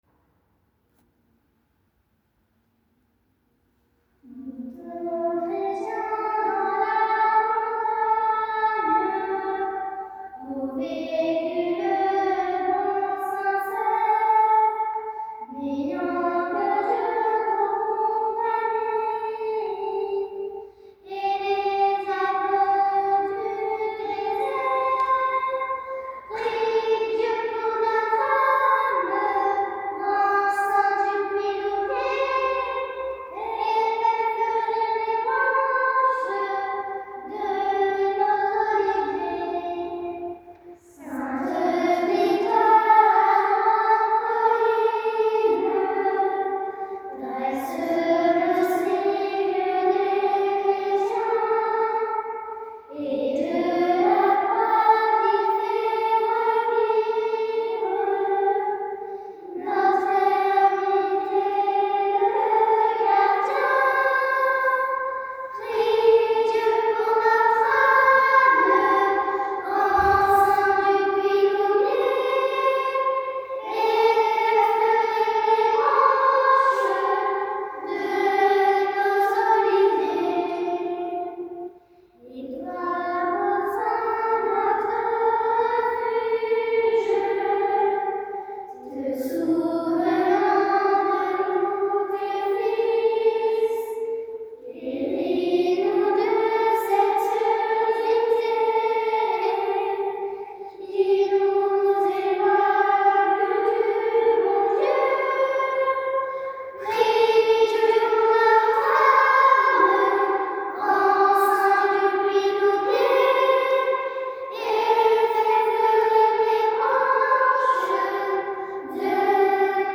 Frédéric Mistral a écrit en provençal un chant à Saint-Ser de Puyloubier chanté sur l’air de Notre-Dame de Grâce de Maillane.